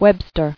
[web·ster]